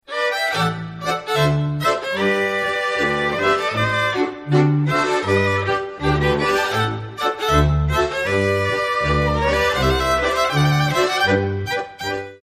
Volkstänze aus Niederösterreich